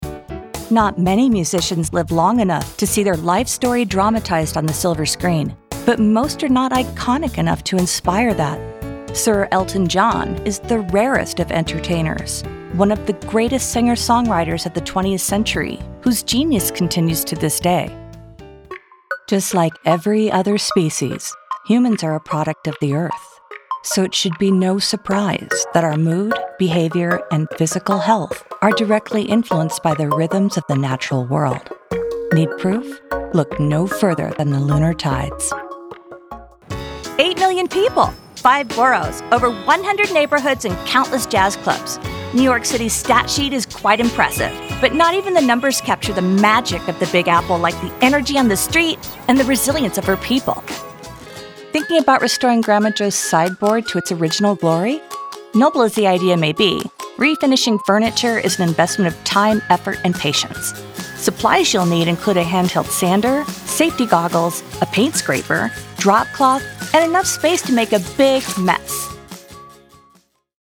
North American female neutral accent
Narration